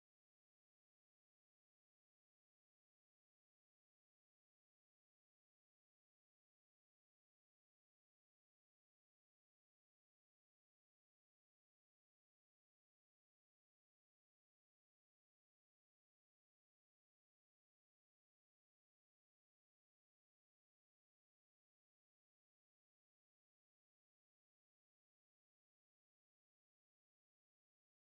Moment of Explosion at RM Palmer Chocolate Factory in West Reading, Pennsylvania.